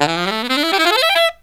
63SAXMD 07-R.wav